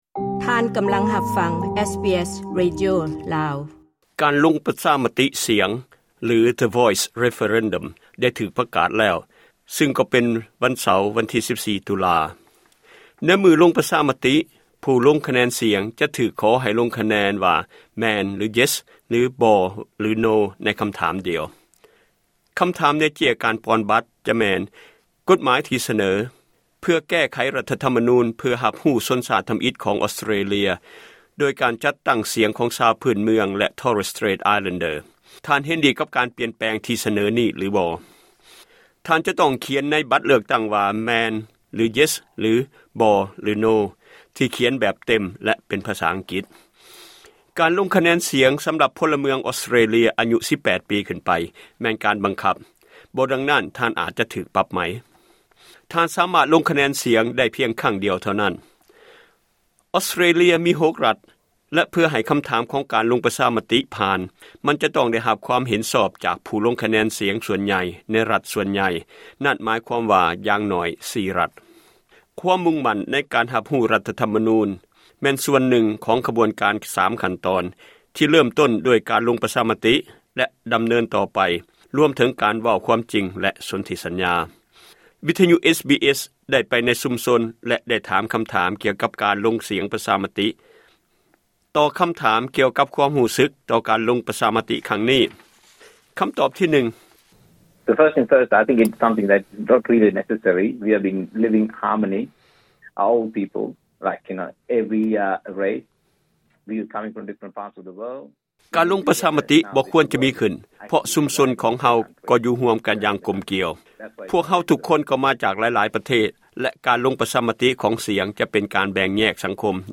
ການລົງປະຊາມະຕິສຽງ (The Voice Referendum) ກໍໃກ້ເຂົ້າມາແລ້ວ.....ຍັງອີກບໍ່ເທົ່າໃດມື້ເທົ່ານັ້ນ. ວິທຍຸ SBS ໄດ້ໄປໃນຊຸມຊົນ ແລະໄດ້ຖາມຄຳຖາມກ່ຽວກັບການລົງປະຊາມະຕິສຽງ (The Voice Referendum).